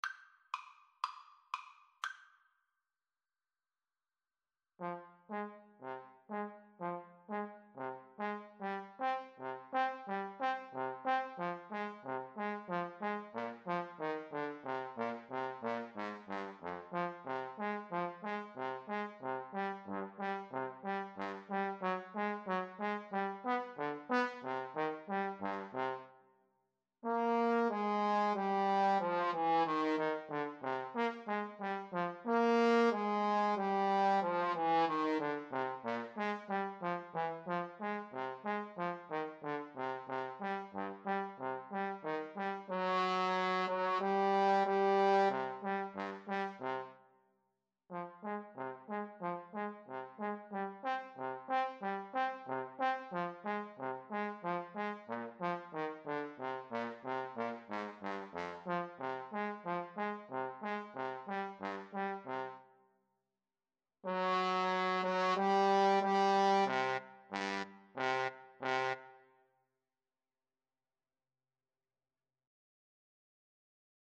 Play (or use space bar on your keyboard) Pause Music Playalong - Player 1 Accompaniment reset tempo print settings full screen
Eb major (Sounding Pitch) F major (Trumpet in Bb) (View more Eb major Music for Trumpet-Trombone Duet )
Steadily (first time) =c.60